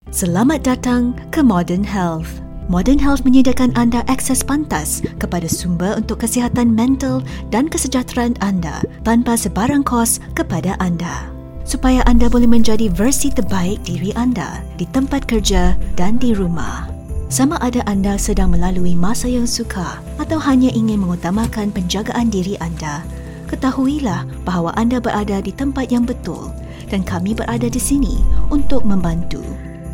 0812Corporate_Explainer_Video_Malay_VO_1.mp3